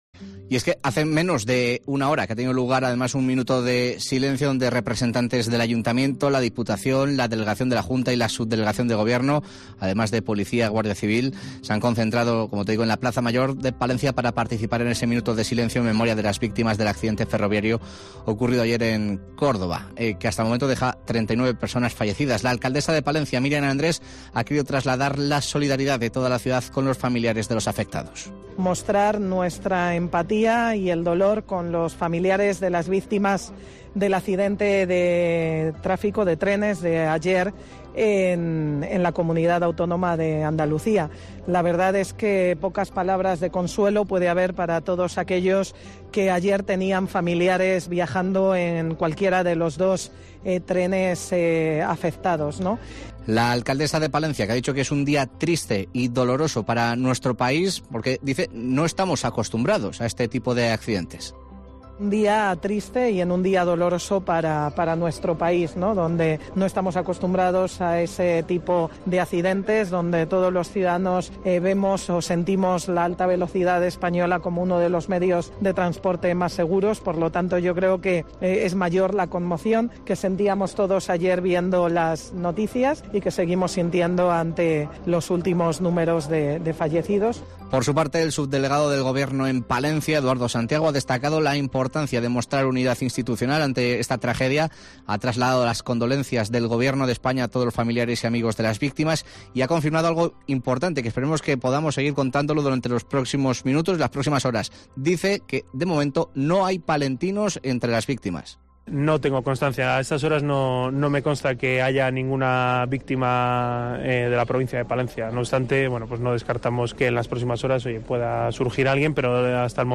Minuto de silencio en la plaza Mayor de Palencia por los fallecidos en el accidente de trenes en Córdoba